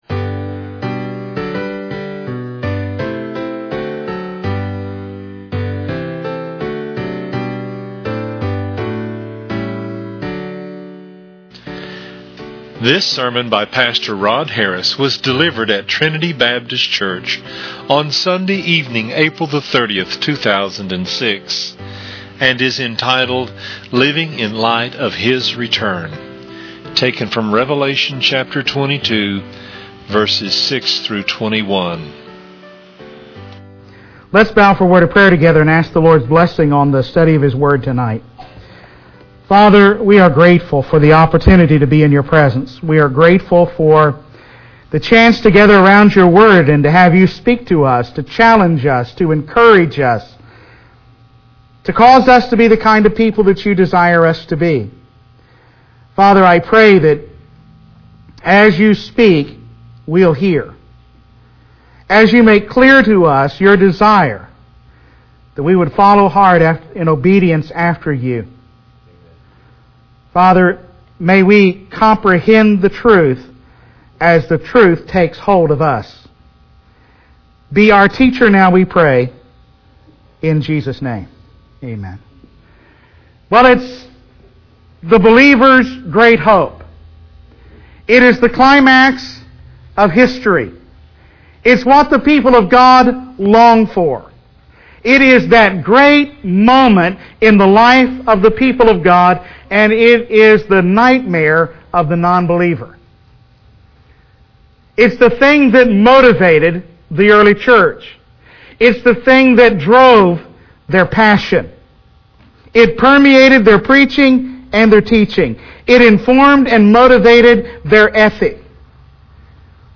Living in Light of His Return This is an exposition of Revelation 22:6-21. This message